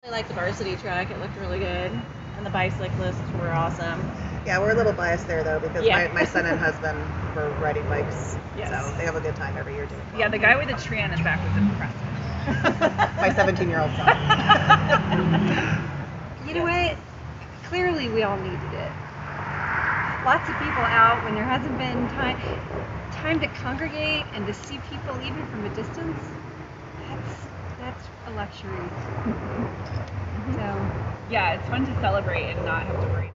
Local residents standing along the parade route expressed that they came out this year, as so many before, because it’s a tradition, especially as there are not many opportunities’ to congregate and remain safe.
Interview-w-Women-along-11th-St.mp3